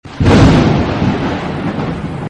thunder.mp3